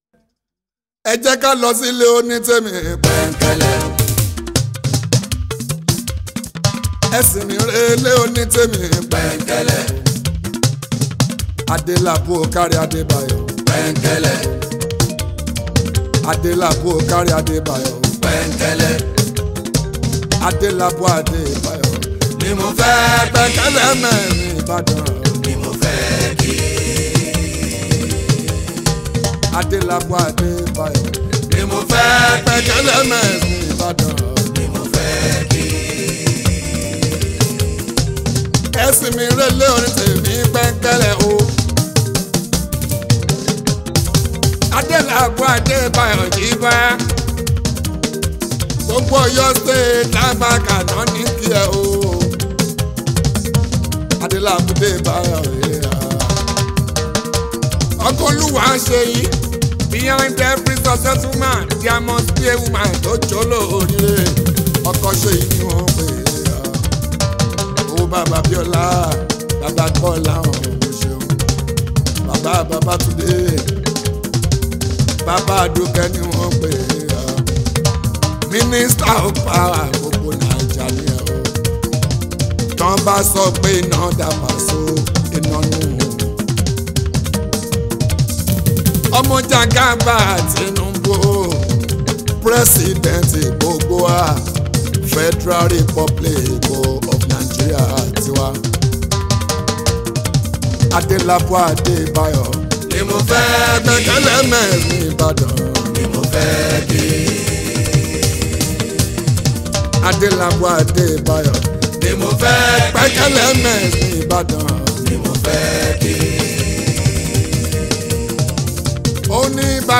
Apala, Fuji, Fuji Mixtape, Highlife